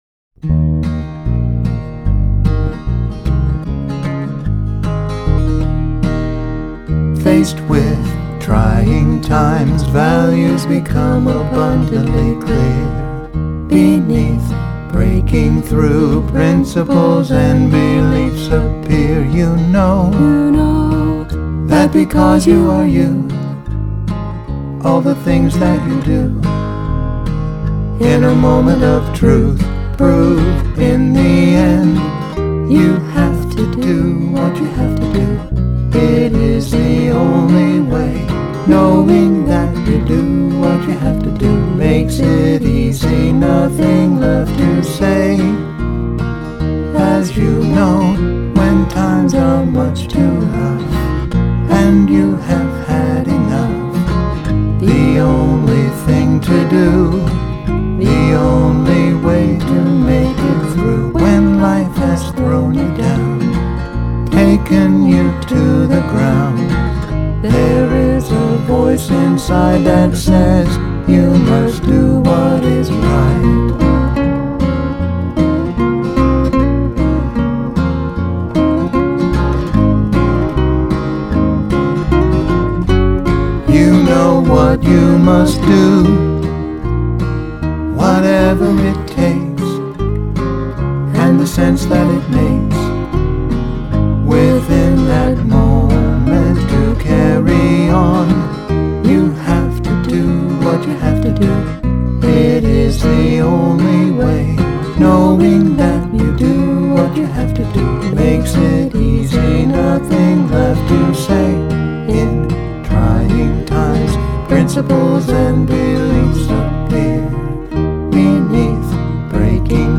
vocals and production
guitar